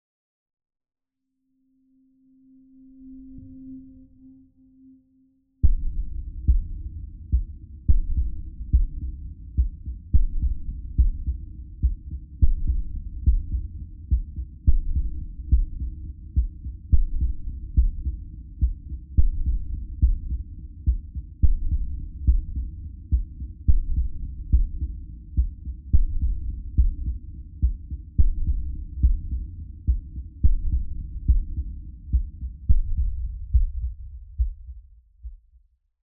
STest1_Right200Hz.wav